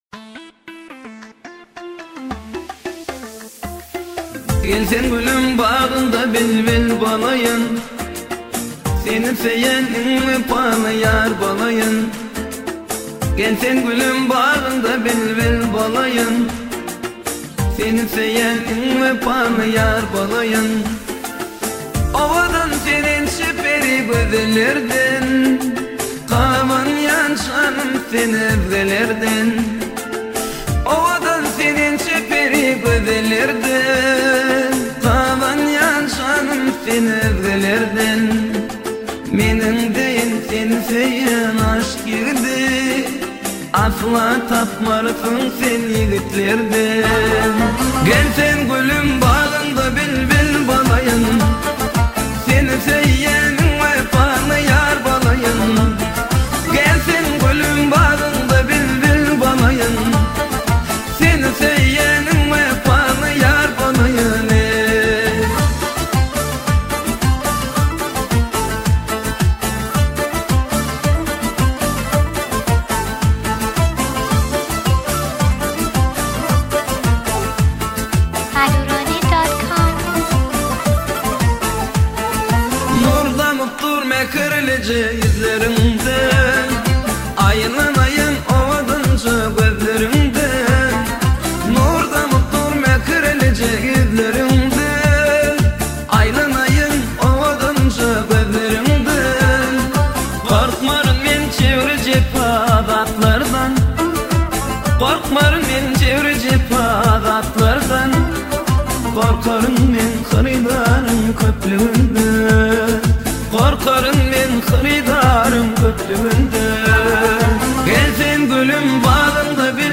یه اهنگ شاد ترکمنی